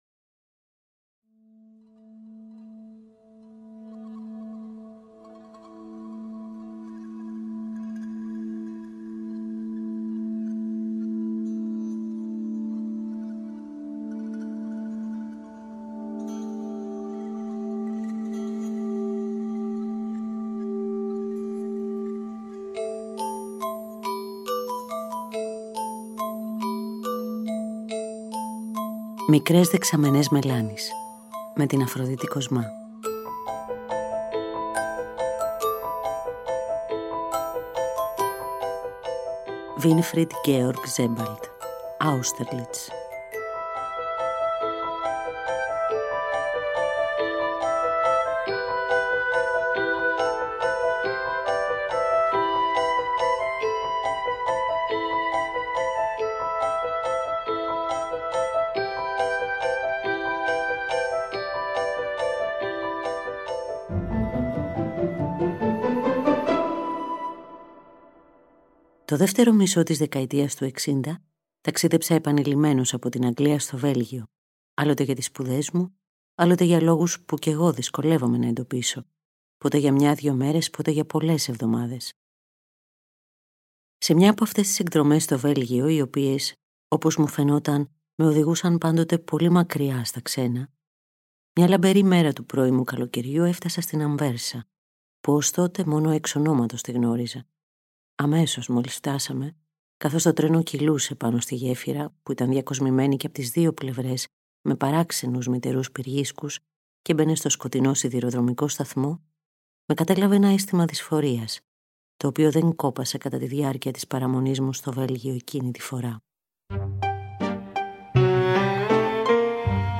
Επιμέλεια, αφήγηση